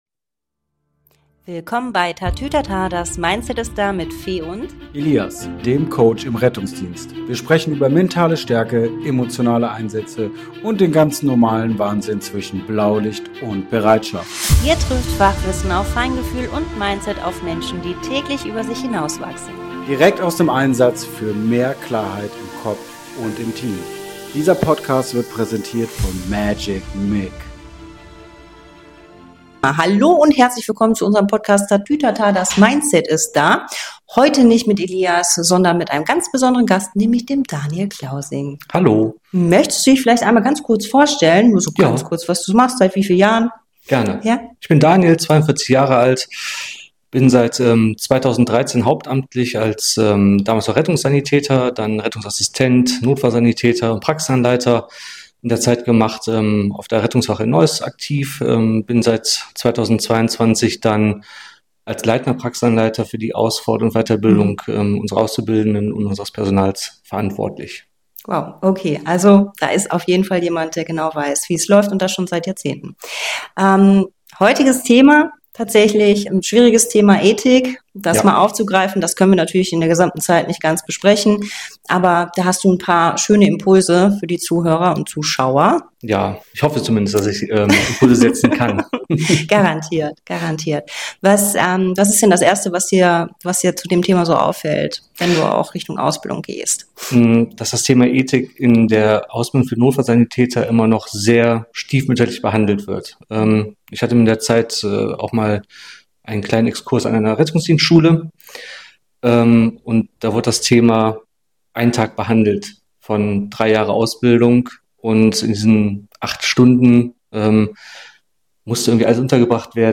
Gemeinsam sprechen die beiden über ethische Fragen im Einsatzalltag: 🩺 Wann ist Helfen richtig?